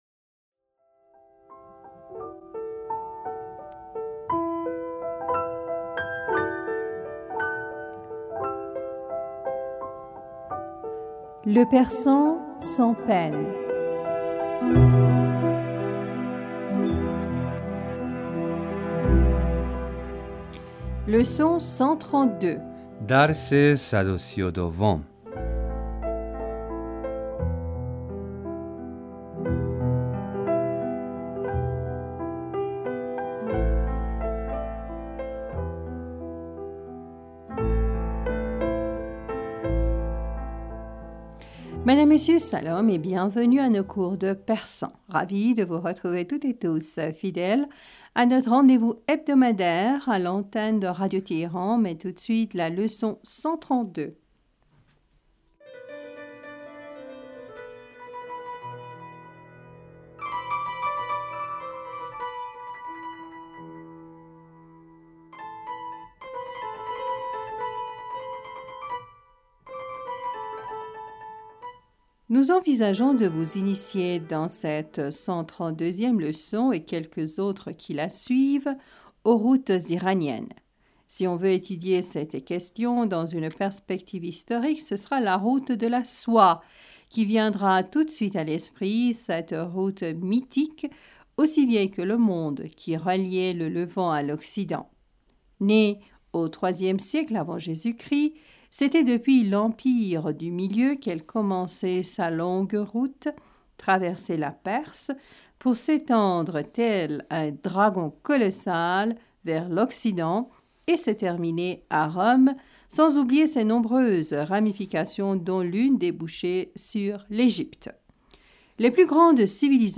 Madame, Monsieur, Salam et bienvenus à nos cours de persan.
Ecoutez et répétez après nous.